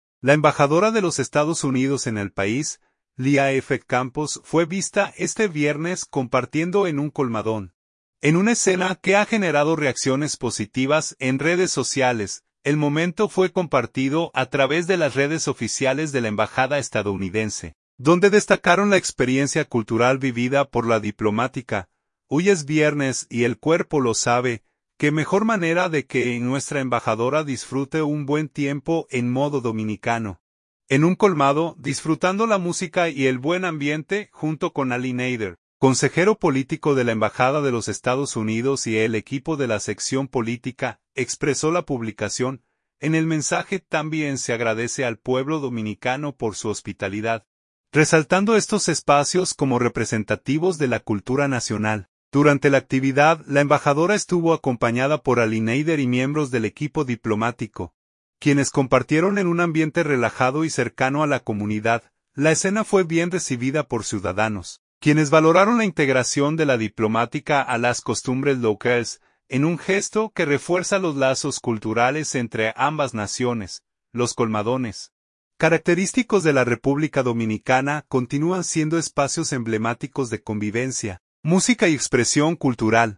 Embajadora de EE.UU. disfruta ambiente de colmadón al estilo dominicano
República Dominicana.– La embajadora de los Estados Unidos en el país, Leah F. Campos, fue vista este viernes compartiendo en un colmadón, en una escena que ha generado reacciones positivas en redes sociales.